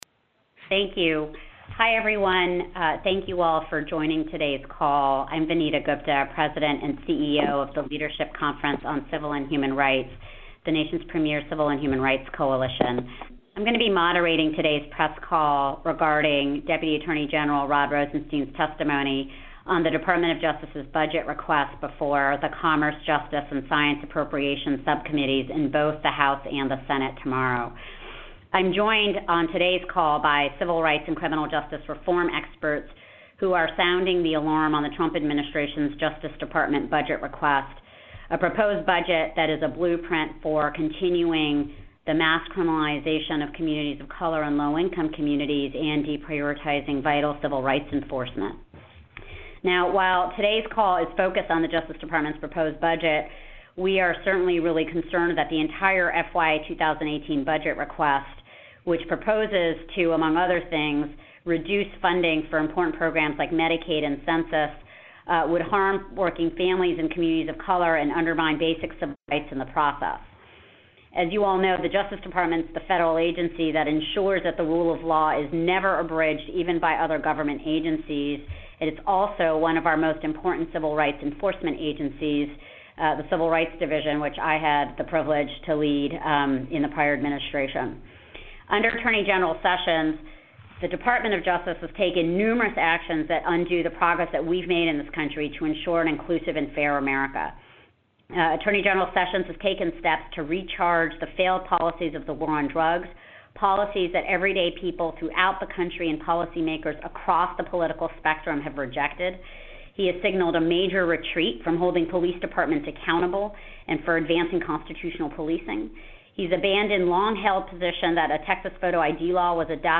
President and CEO Vanita Gupta of The Leadership Conference on Leadership and Human Rights is on the phone with civil rights and criminal justice reform experts who are sounding the alarm on the Trump Administration's DOJ budget request that is perpetuating the mass criminalization and incarceration of colored and low income communities and deprioritizing vital civil rights enforcenent.